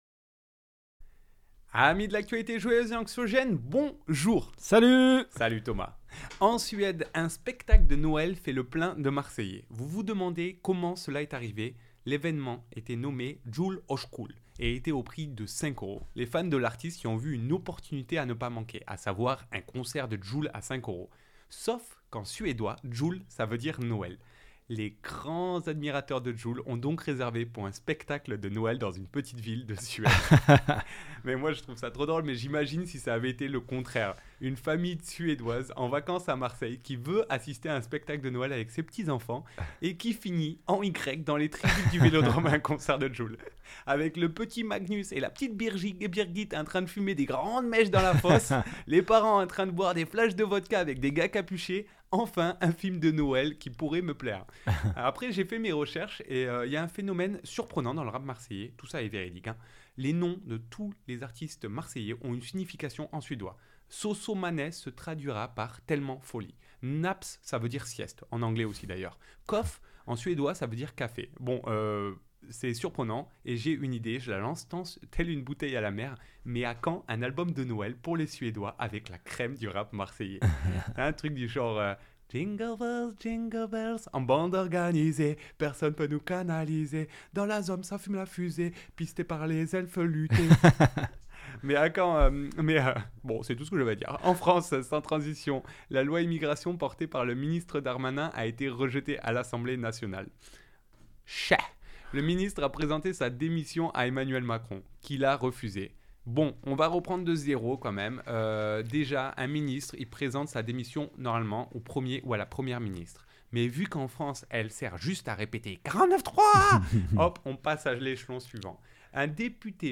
chronique humoristique